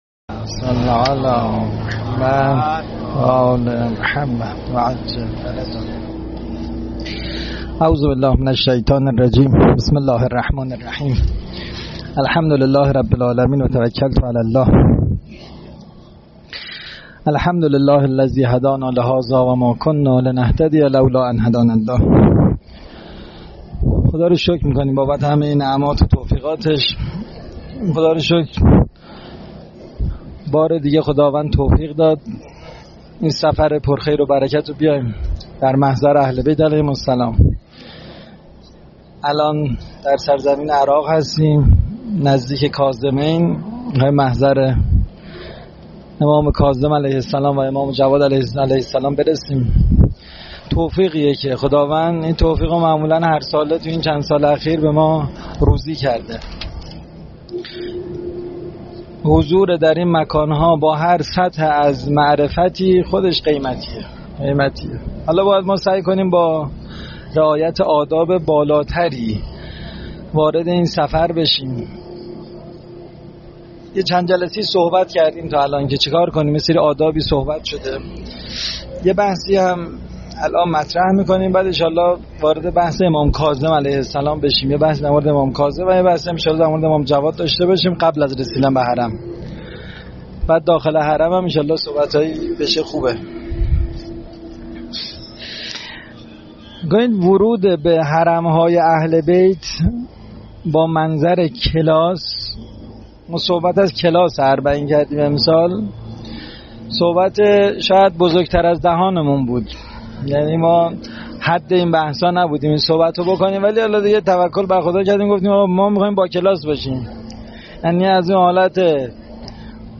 کلاس اربعین 3